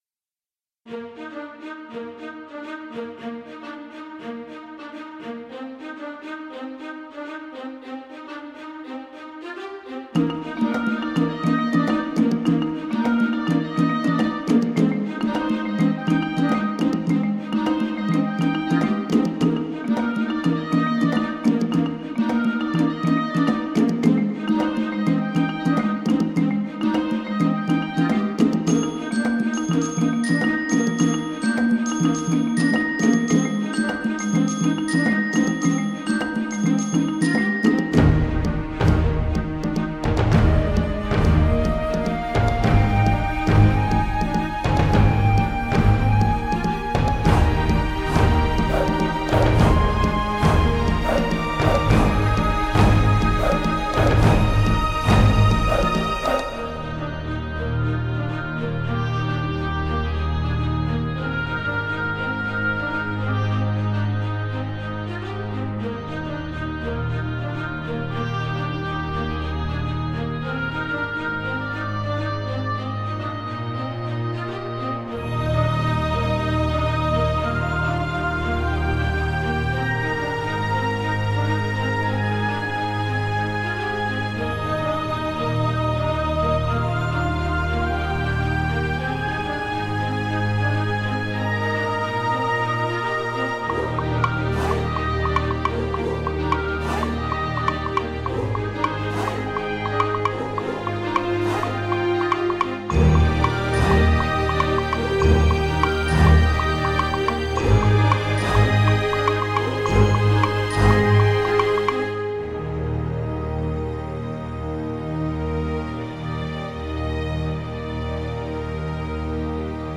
Stage 1 Theme-Orchestral Paradise Mix.